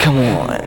VOX SHORTS-1 0004.wav